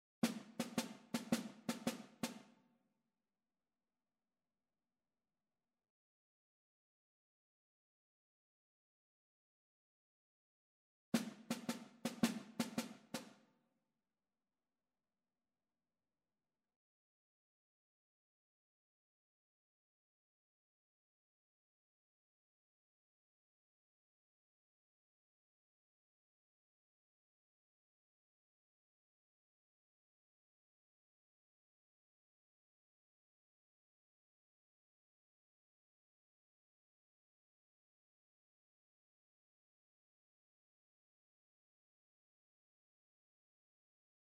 Schumann: Śmiały jeździec (na wiolonczelę i fortepian)
Symulacja akompaniamentu
schumann_smialy-jezdziec_nuty_vc-pf-acc..mp3